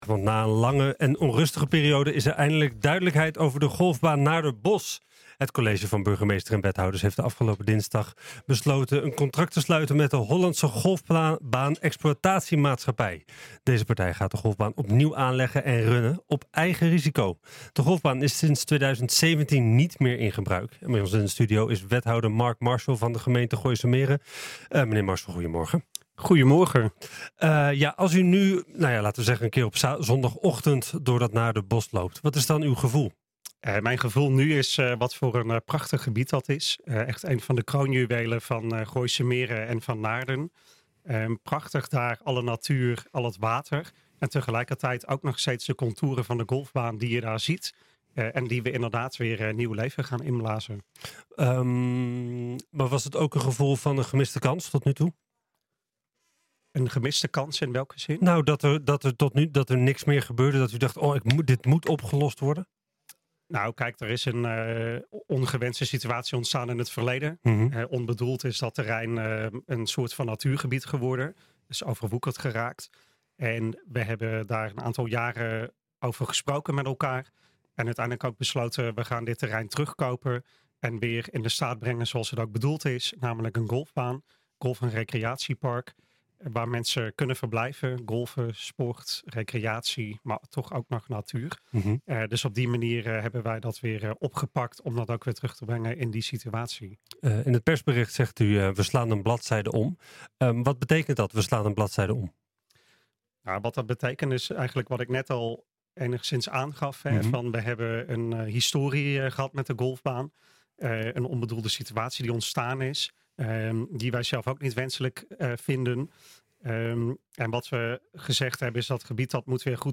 Bij ons in de studio is wethouder Mark Marshall van de gemeente Gooise Meren.